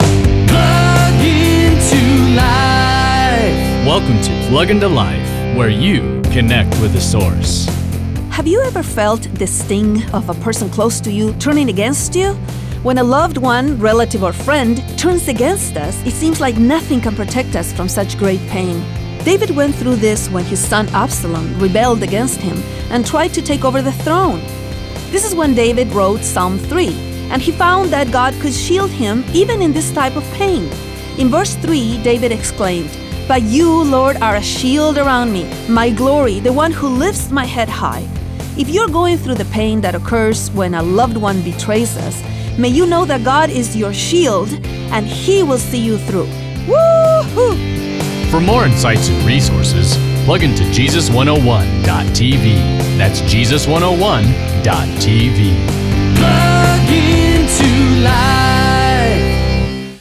Enjoy these 1 minute daily devotionals for those on the go to help you refocus your life on Jesus! Jesus 101 exists to introduce people to the real Jesus of the gospel and to equip them to share His love with others!